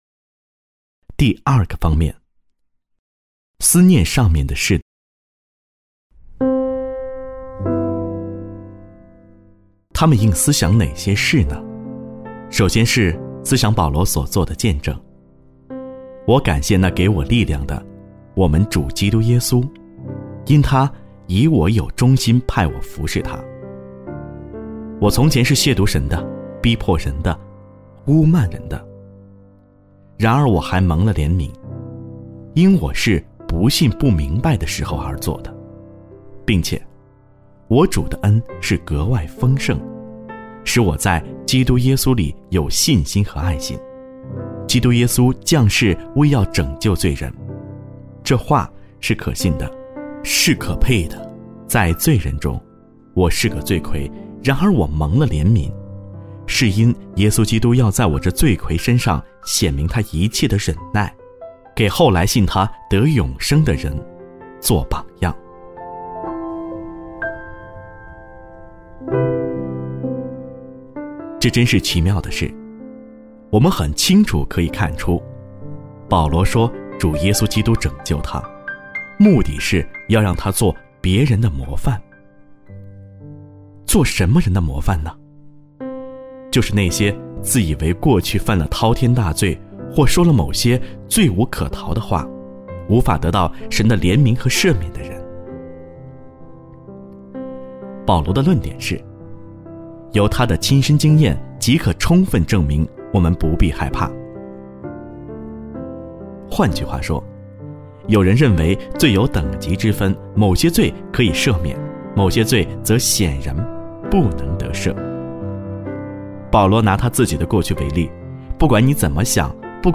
首页 > 有声书 | 灵性低潮录 | 灵性生活 > 灵性低潮录 第七章 ：往事难忘（2）上：思念上面的事